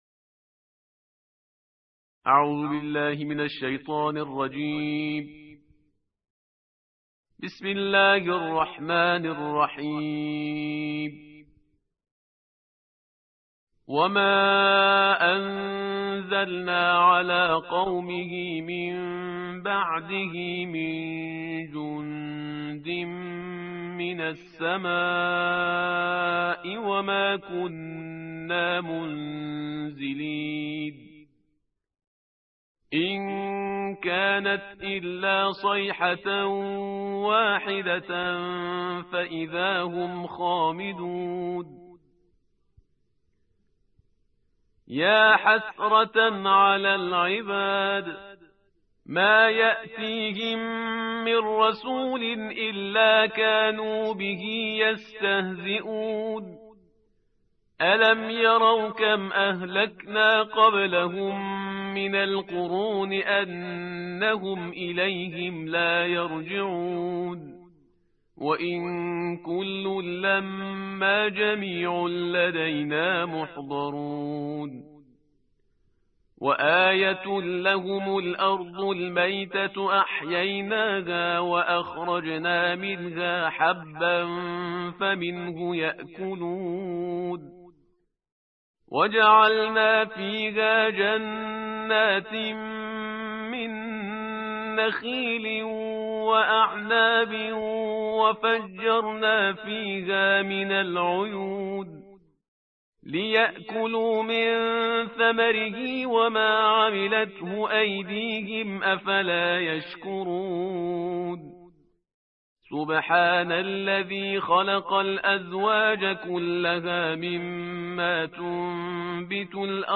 ترتیل جزء «بیست و سوم» قرآن کریم